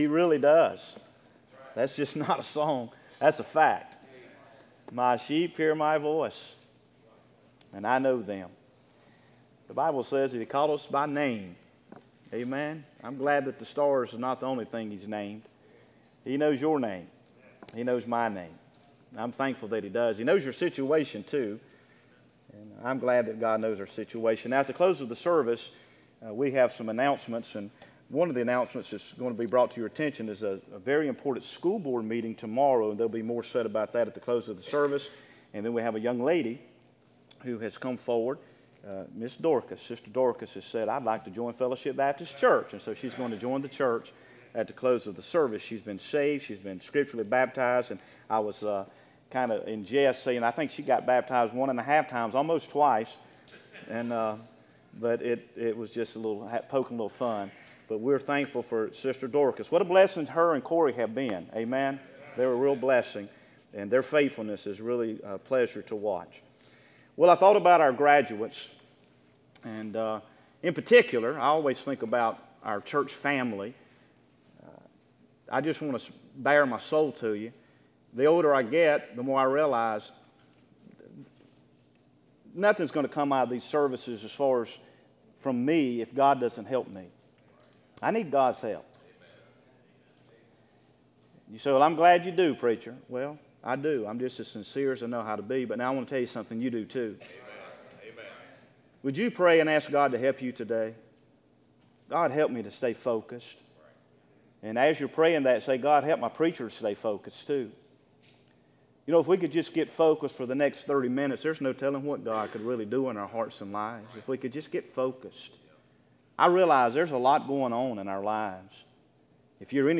Sermons Archive • Page 41 of 167 • Fellowship Baptist Church - Madison, Virginia